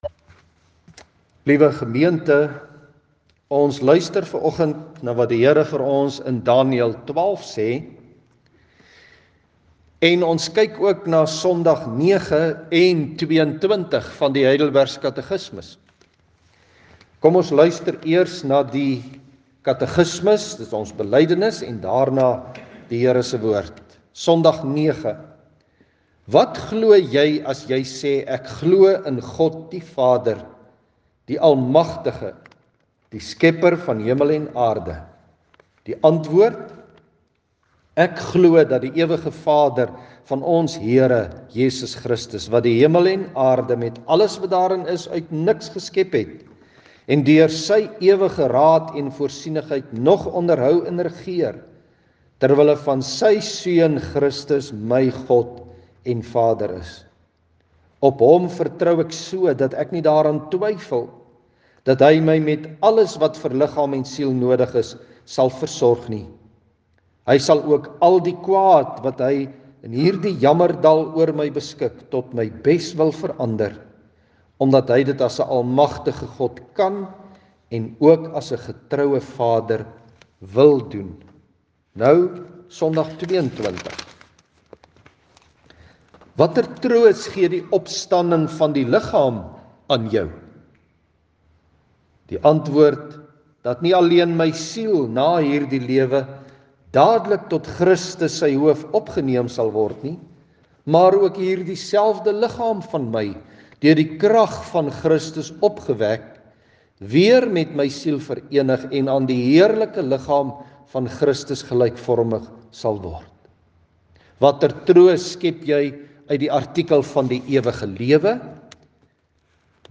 Sekerheid oor die toekoms, die ewige toekoms in ‘n baie onseker land en wêreld, is waaroor vanoggend se preek gaan.